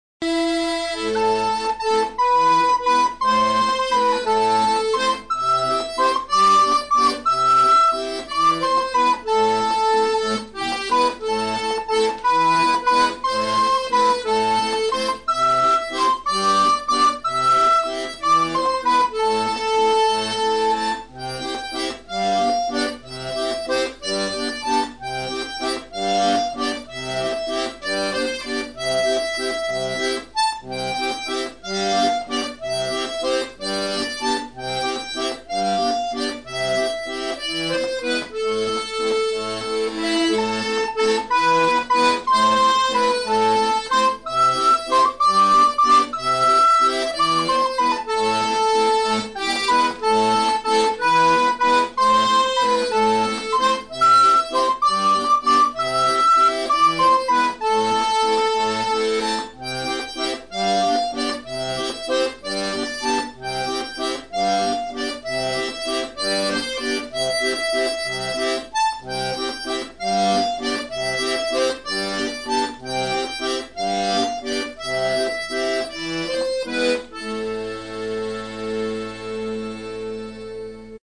morceau joué à la flûte à bec
accompagné à l'accordéon diatonique